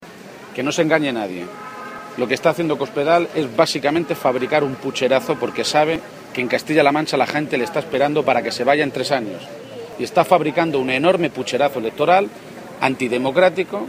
García-Page se ha pronunciado así en Puertollano, localidad a la que ha acudido para participar en sus fiestas patronales, un escenario en el que ha criticado el afán de Cospedal por querer acabar con la política o desprestigiarla, quizá, ha sugerido, porque “probablemente en toda España va a costar encontrar a alguien con menos capacidad de ejemplo de cómo se debe hacer política como la señora Cospedal”.